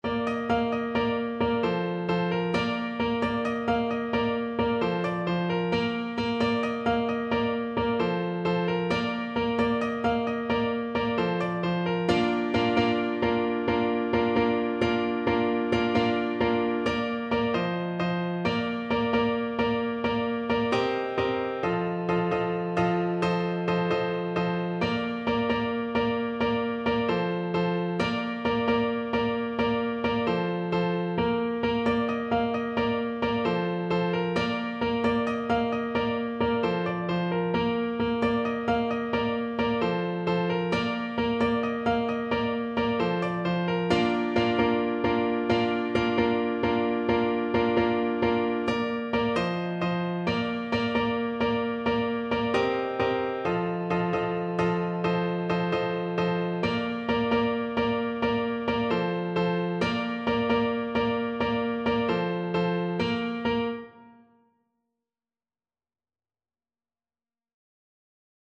Clarinet version
7/8 (View more 7/8 Music)
Allegro =132 (View more music marked Allegro)